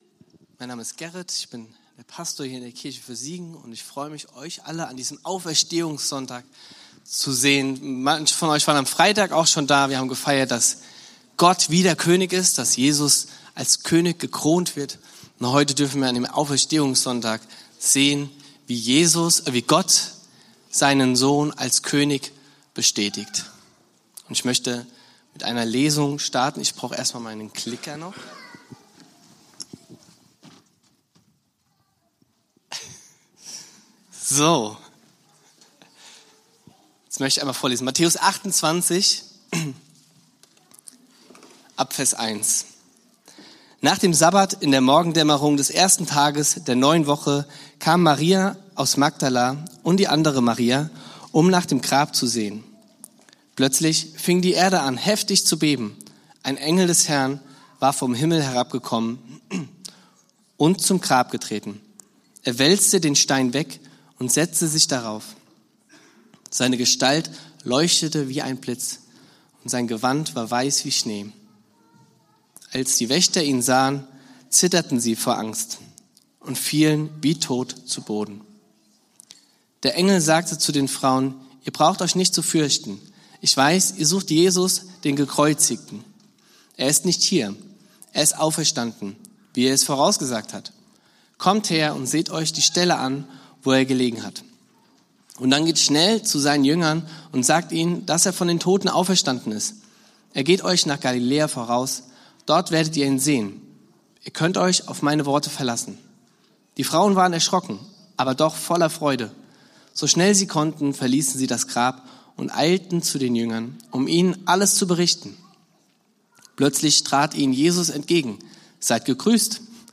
Predigt vom 05.04.2026 in der Kirche für Siegen